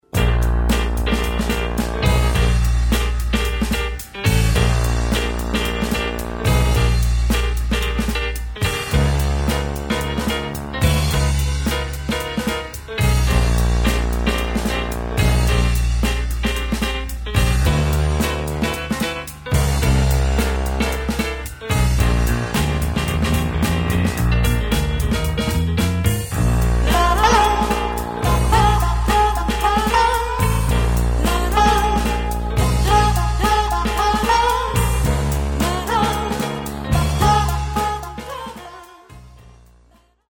Classic Soundtrack!